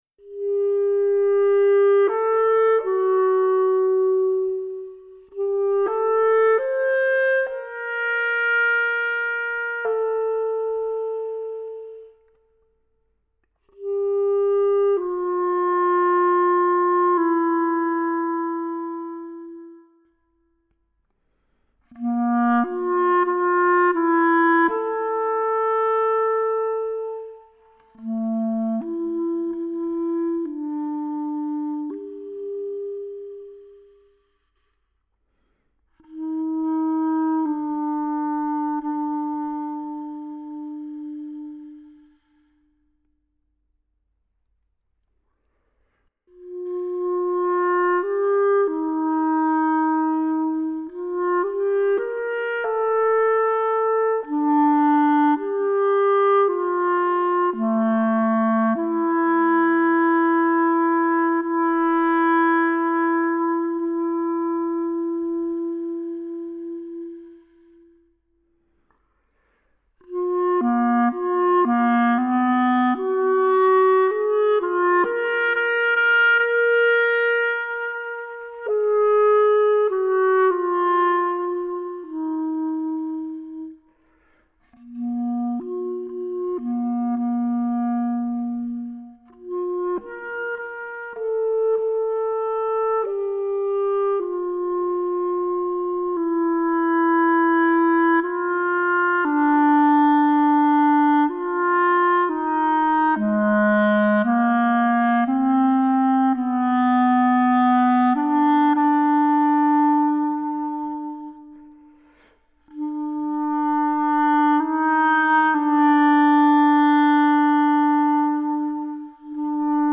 Słuchowisko (fragment)
Klarnety